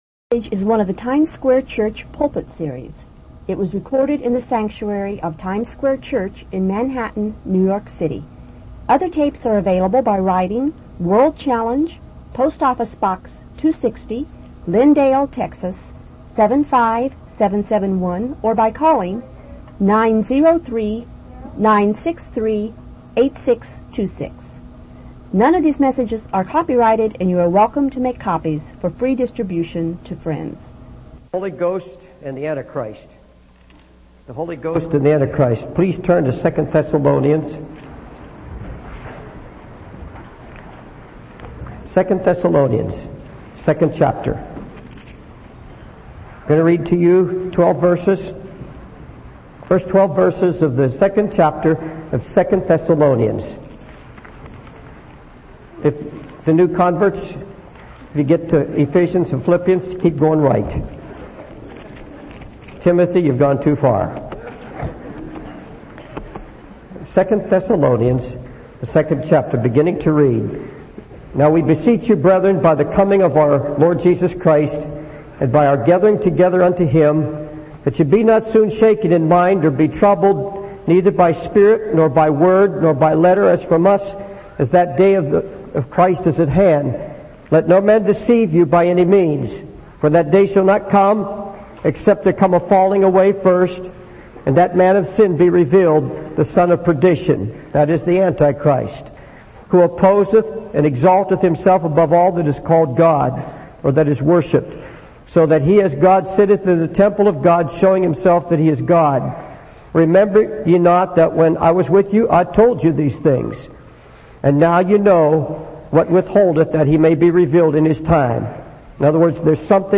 In this sermon, the preacher acknowledges the Holy Spirit as the ruler of his life and the one who can deliver people from sin.